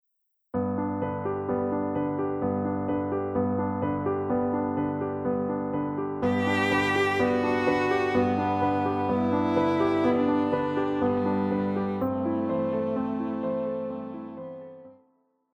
Classical
Cello
Piano
Solo with accompaniment